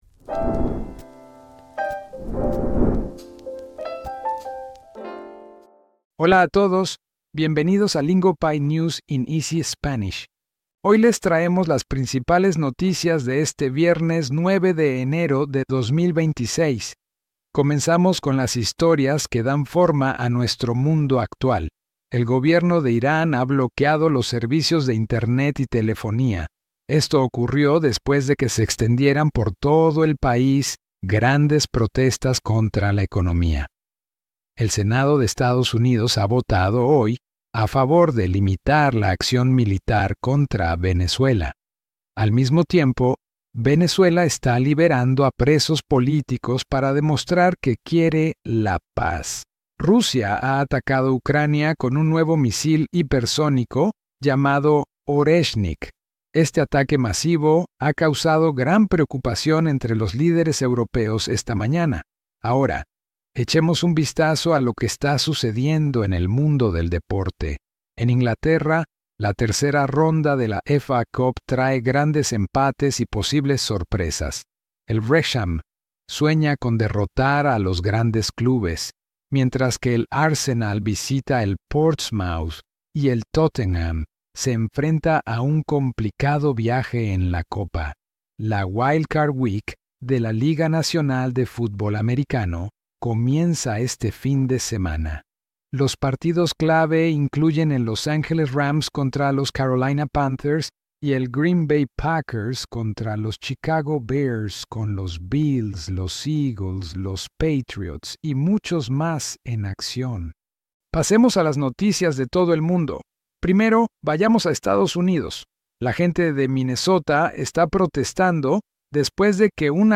We keep it clear, we keep it natural, and we keep it moving.